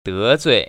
[dé‧zui] 더쭈이